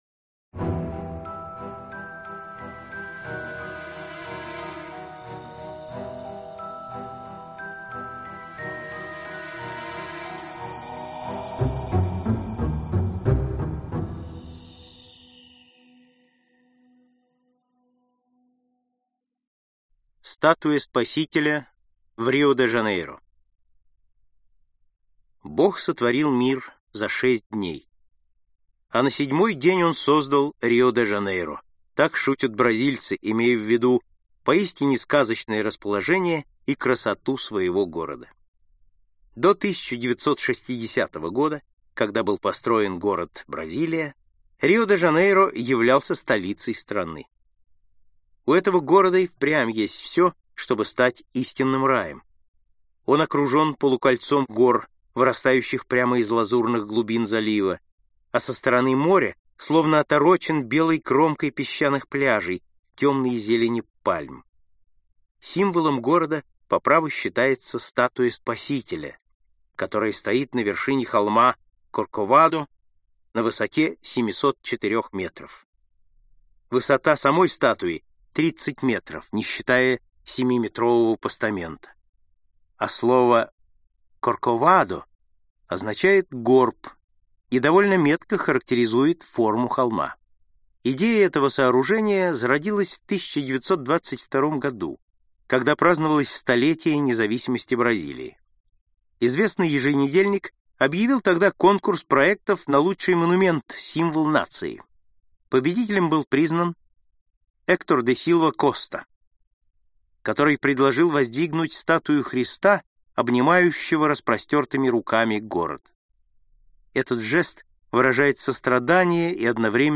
Аудиокнига По странам и континентам | Библиотека аудиокниг